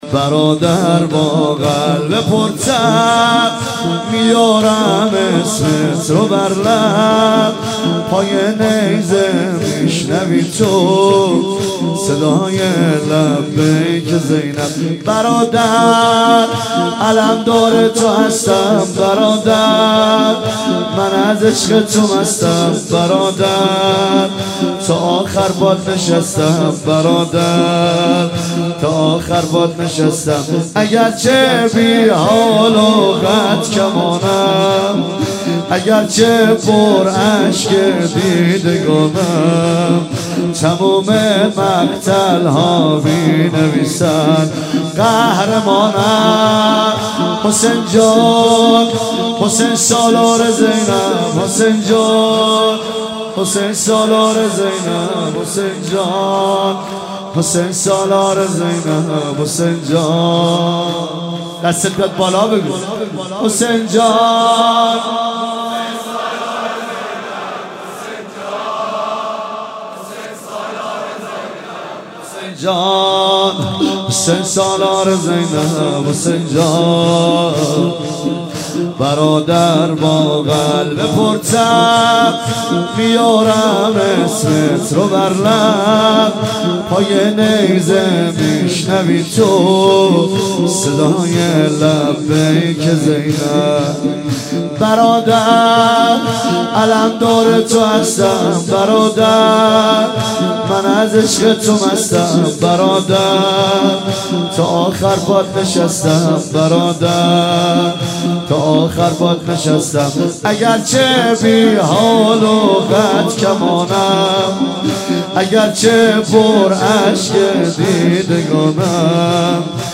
محرم 1395 هیئت بیت الرقیه زاهدان
مراسم شام غریبان ۱۳۹۵
هیئت بیت الرقیه زاهدان